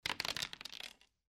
dice2.mp3